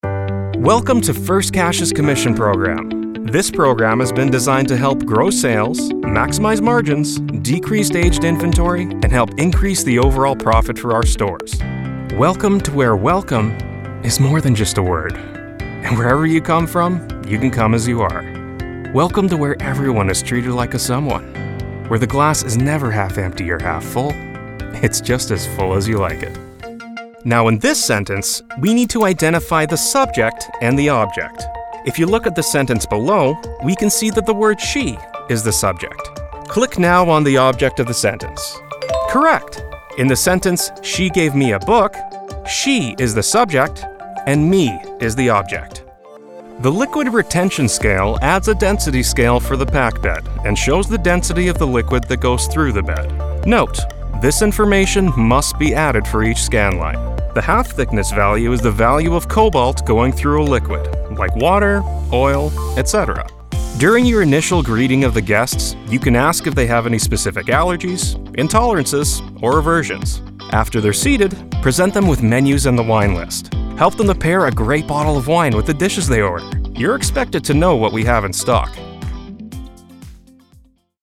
sehr variabel, dunkel, sonor, souverän, markant
Mittel minus (25-45)
eLearning/Corporate
Tutorial, Presentation, Overlay, Narrative